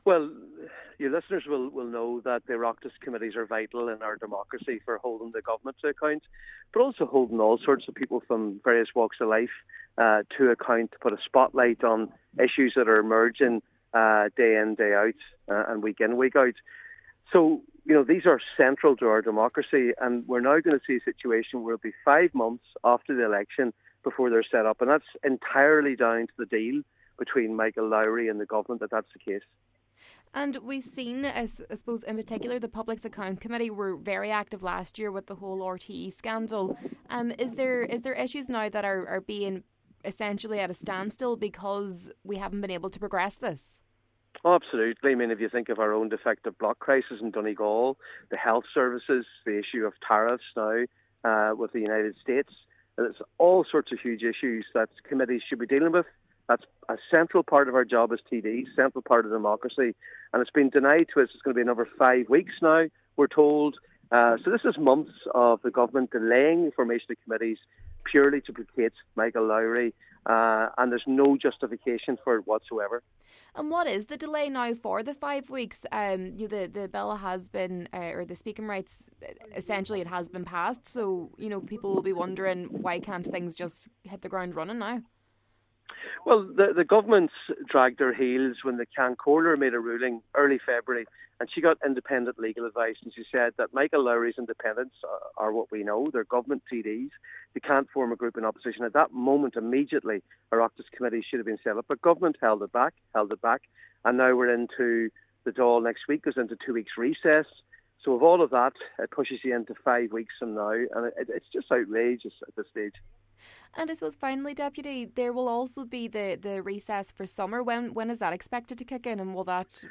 The Donegal TD was speaking in response to the confirmation that Oireachtas Committees will not be established for another month, on May 8th.